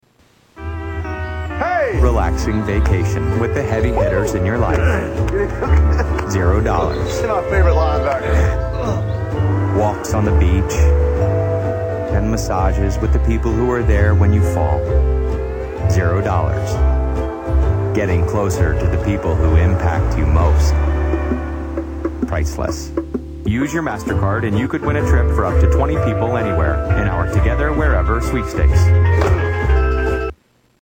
Spa Commercial
Tags: Media MasterCard Advertisement Commercial MasterCard Clips